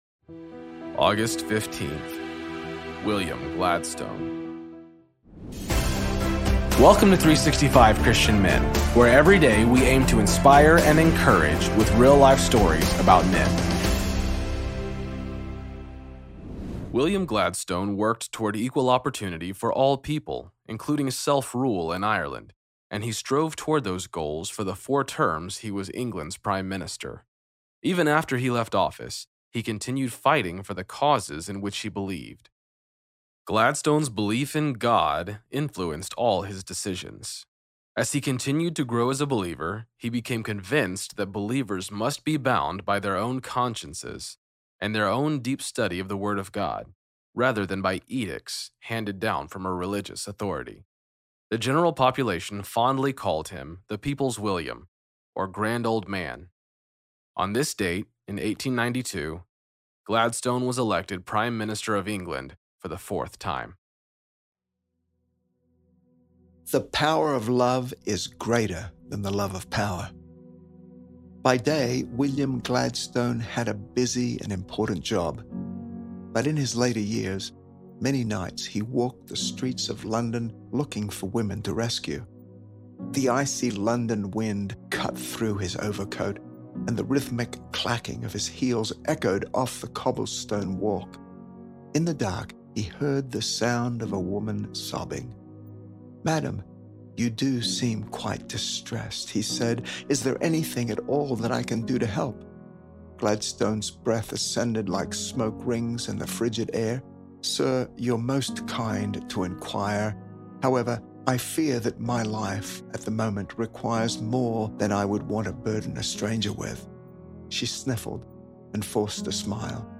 Story read by
Introduction read by